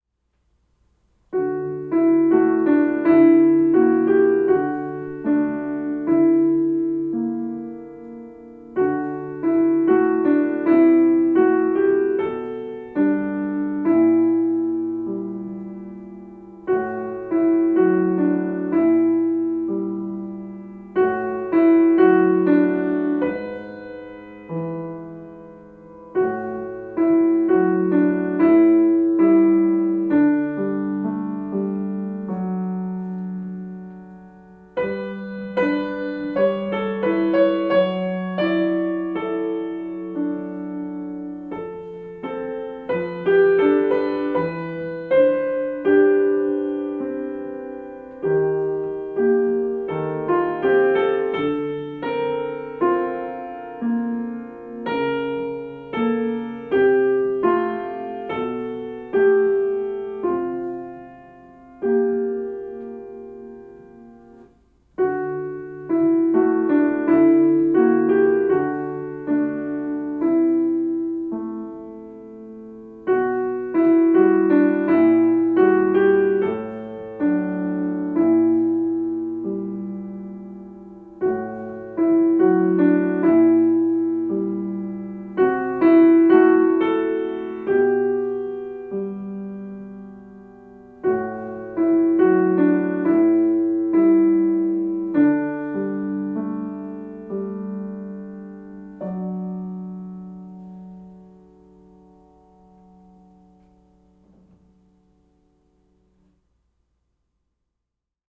lyrical early-intermediate piano solo
Keep the pace gentle and unhurried.
Rubato is key.